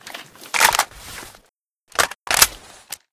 aks74u_reload.wav